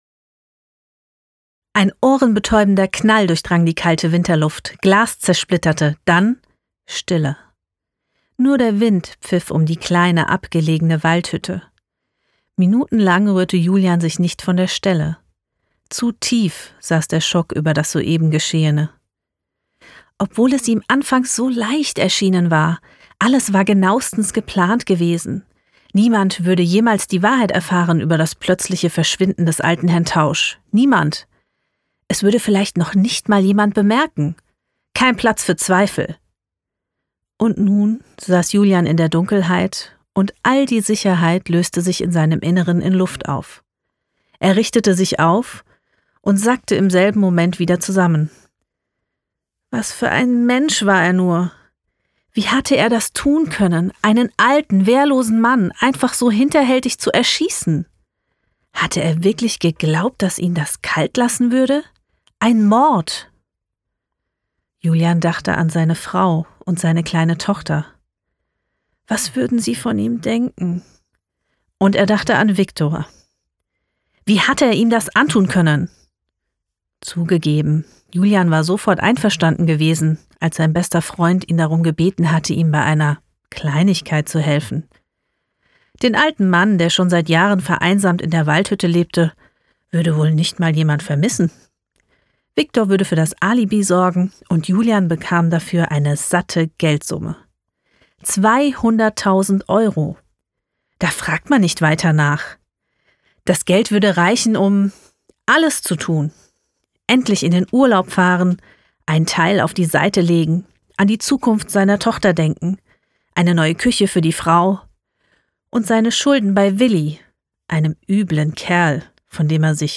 Professionelle Studiosprecherin.
Stimmungstext